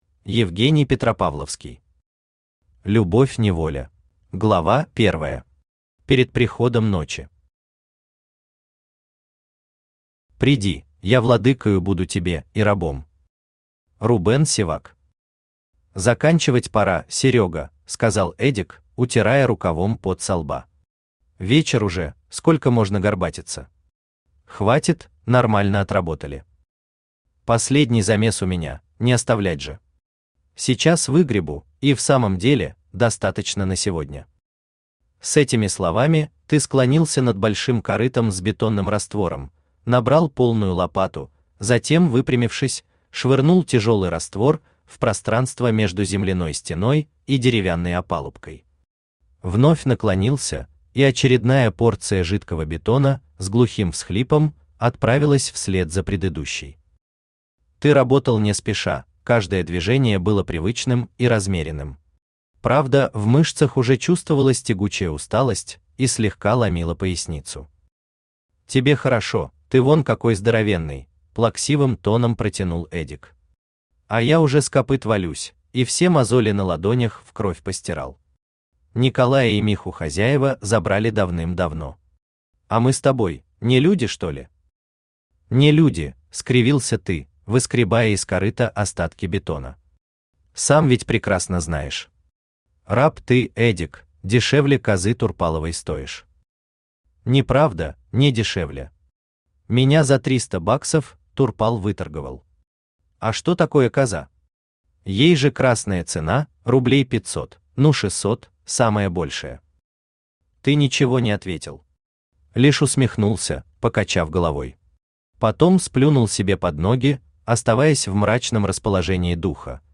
Аудиокнига Любовь-неволя | Библиотека аудиокниг
Aудиокнига Любовь-неволя Автор Евгений Петропавловский Читает аудиокнигу Авточтец ЛитРес.